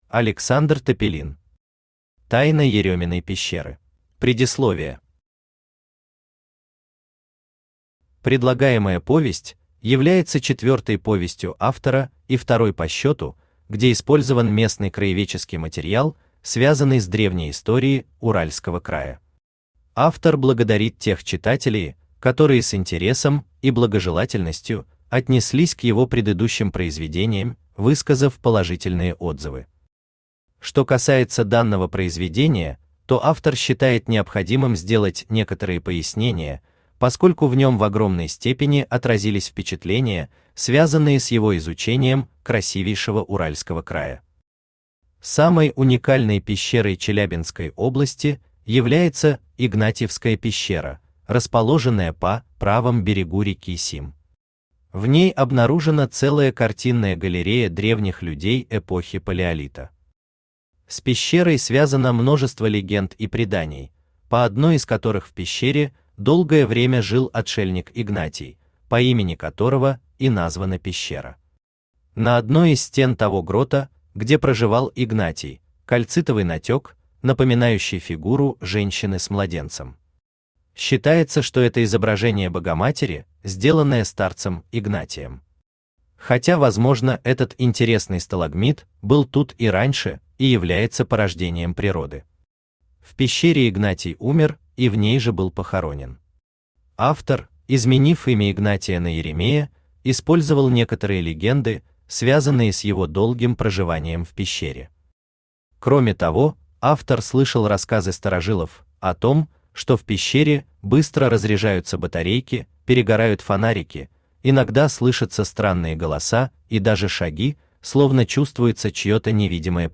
Аудиокнига Тайна Ерёминой пещеры | Библиотека аудиокниг
Aудиокнига Тайна Ерёминой пещеры Автор Александр Иванович Тапилин Читает аудиокнигу Авточтец ЛитРес.